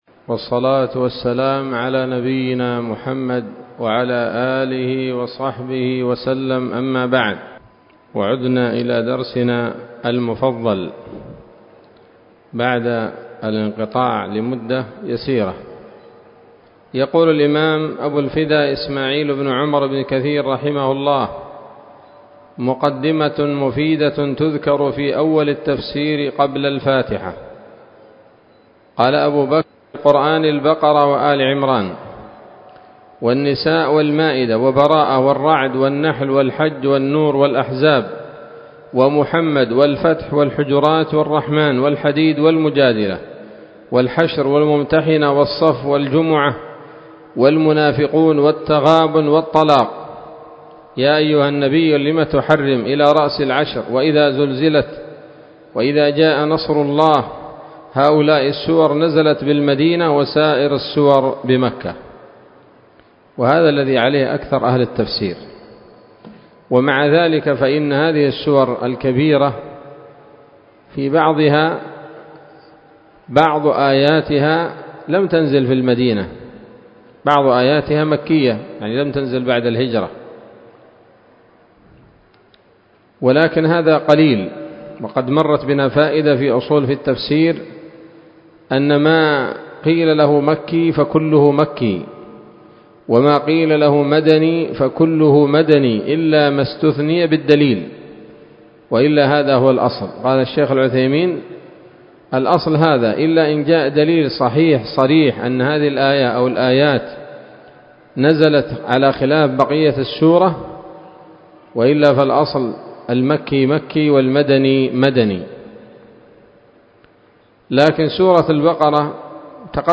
الدرس الأول من سورة الفاتحة من تفسير ابن كثير رحمه الله تعالى